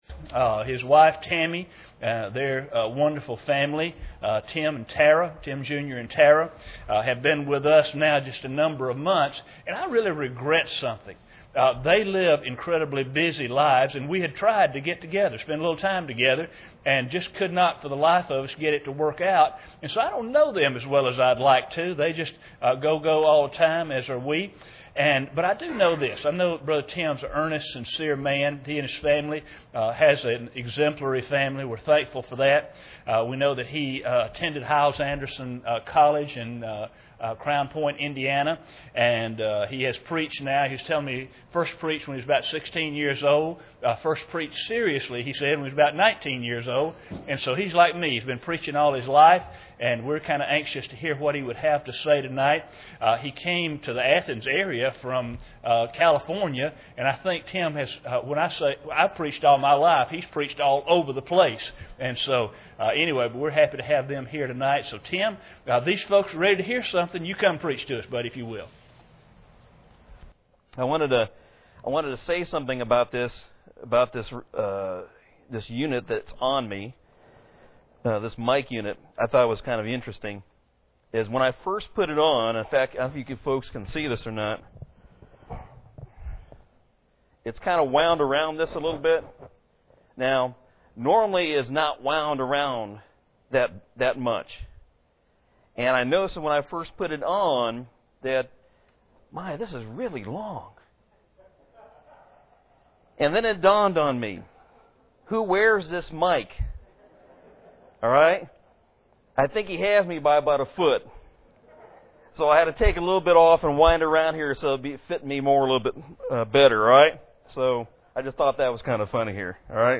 Mark 4:35-41 Service Type: Sunday Evening Bible Text